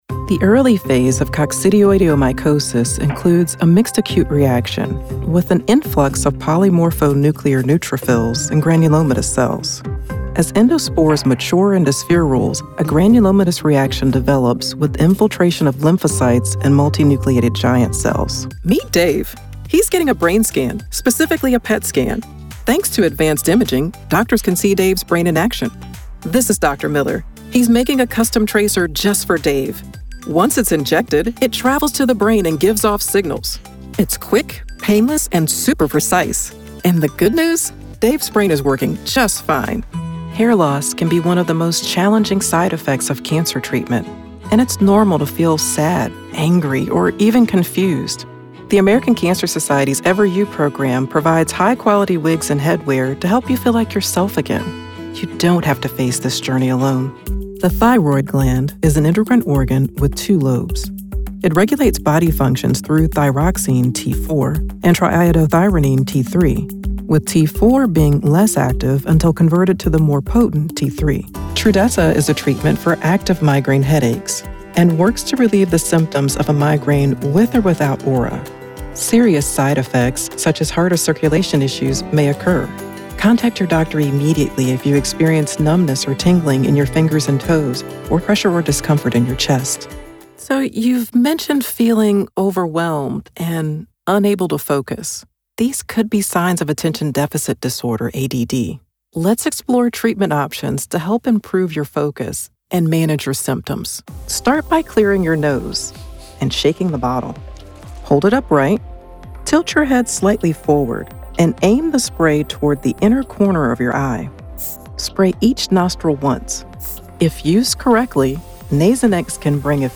Atlanta-based voice actor with healthcare expertise, specializing in medical, eLearning, and corporate narration. Award-nominated, professional home studio.
Demos & Audio Samples